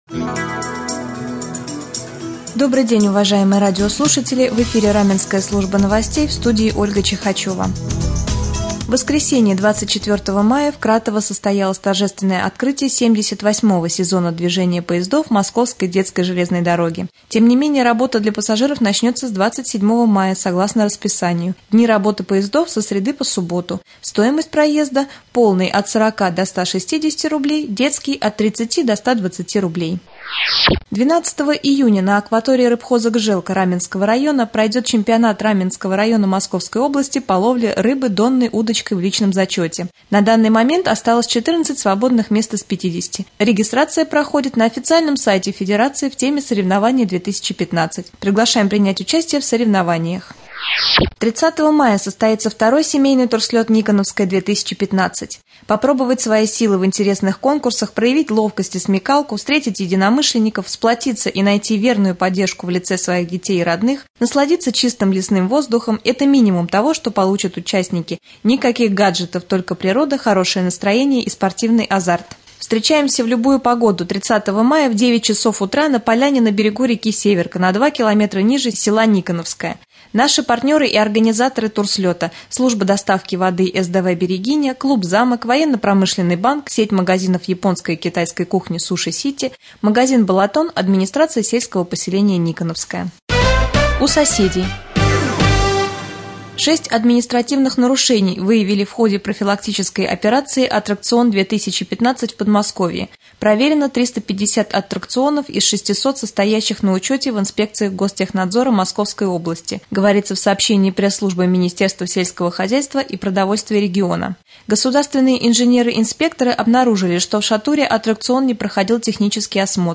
Новостной блок